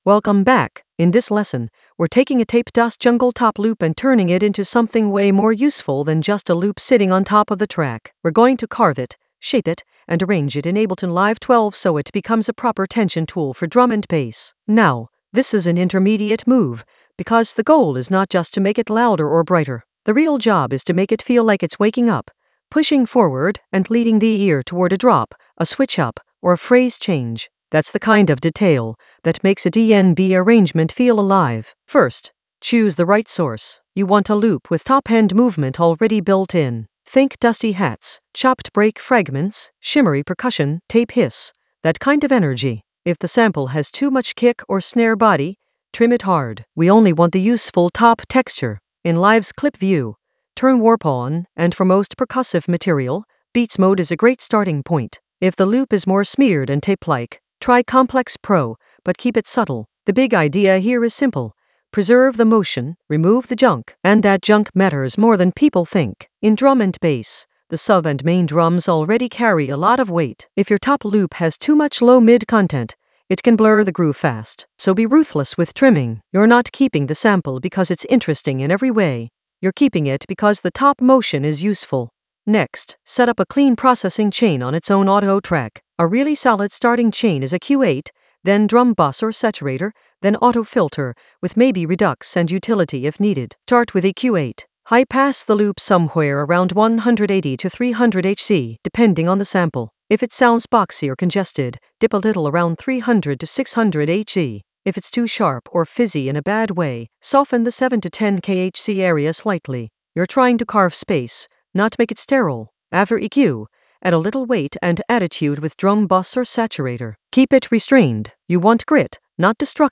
An AI-generated intermediate Ableton lesson focused on Tape Dust jungle top loop: carve and arrange in Ableton Live 12 in the Risers area of drum and bass production.
Narrated lesson audio
The voice track includes the tutorial plus extra teacher commentary.